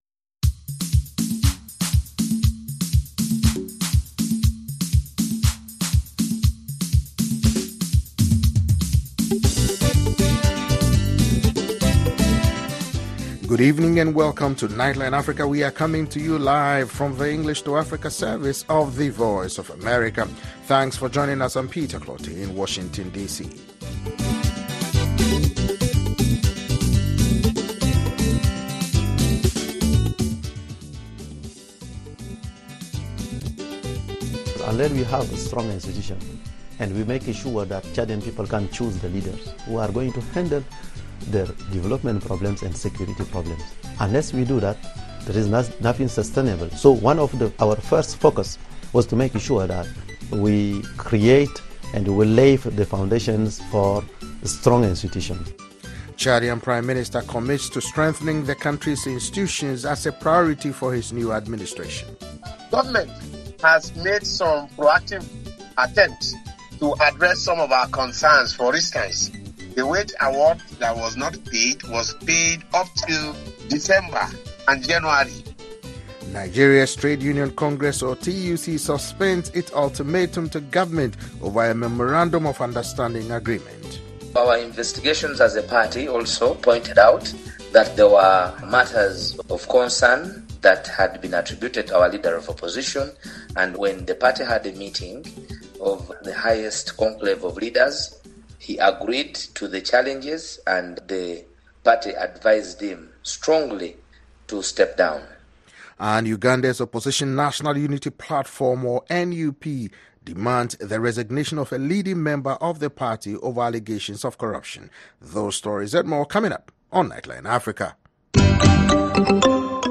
Join our host and correspondents from Washington and across Africa as they bring you in-depth interviews, news reports, analysis and features on this 60-minute news magazine show.